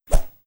Woosh 02.wav